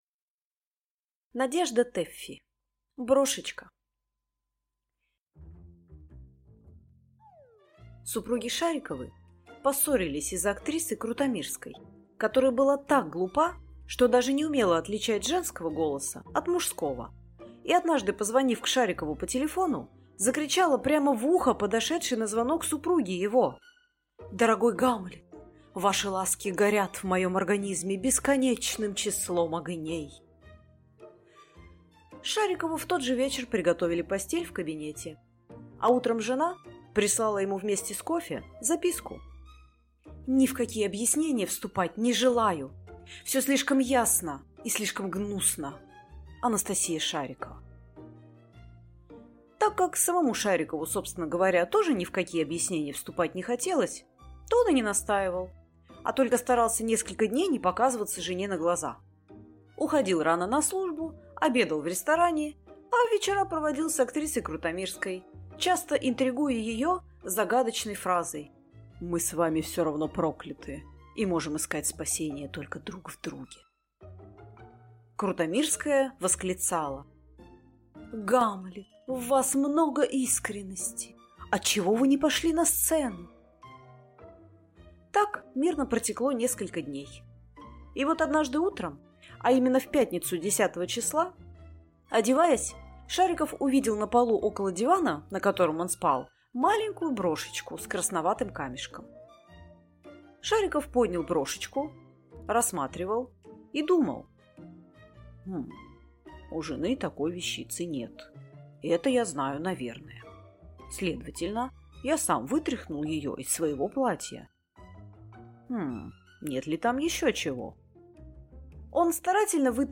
Аудиокнига Брошечка | Библиотека аудиокниг